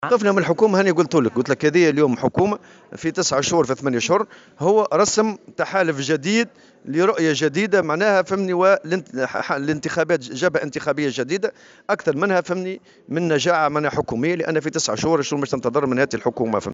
وأضاف الطبوبي في تصريح إعلامي اليوم الثلاثاء على هامش الندوة الوطنية للهجرة، أنه لا يمكن لهذه الحكومة أن تقدّم شيئا في ظرف وجيز، وفق تعبيره.